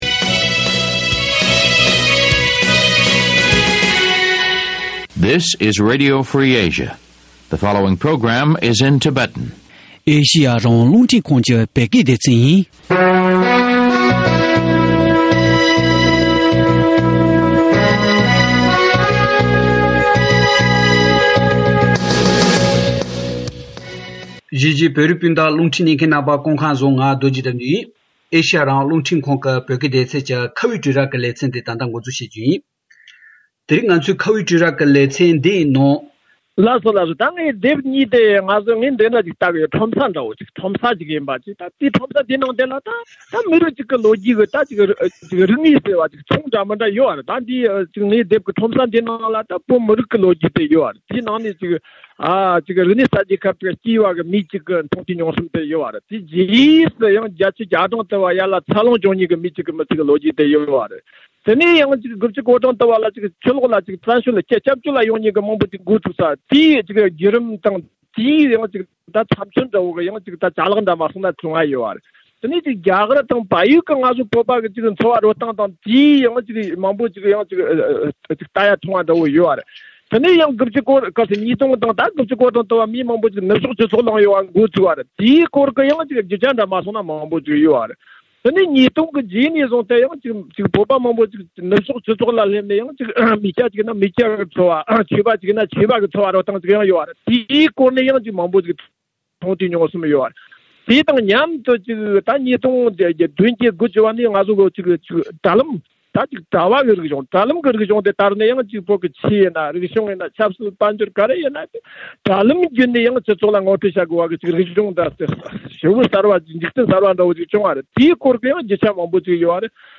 བཅར་འདྲི་བྱས་པ་ཞིག་ཡིན།